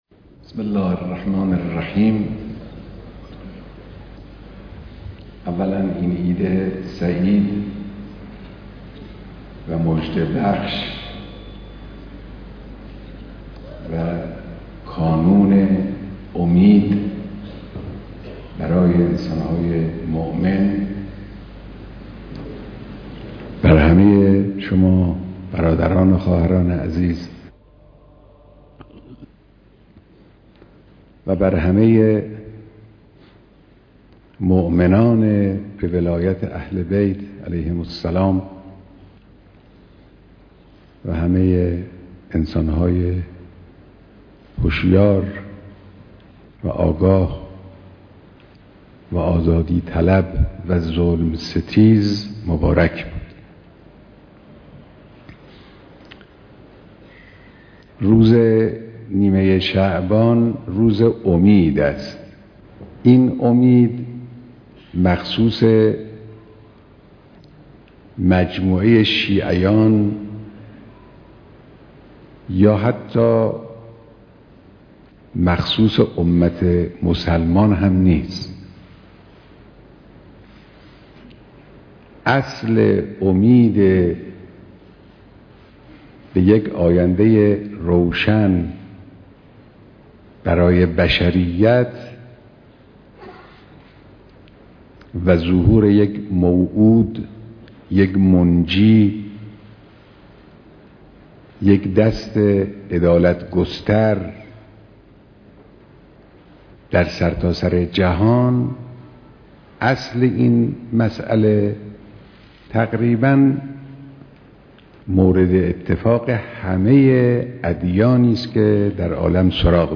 دیدار رایزنان فرهنگی خارج از کشور، وزیر، معاونین و مسئولین آموزش و پرورش